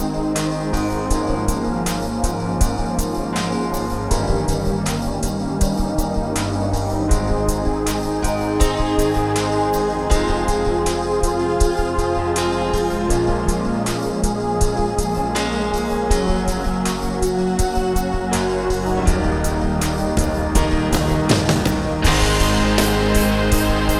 Two Semitones Down Pop (1970s) 4:50 Buy £1.50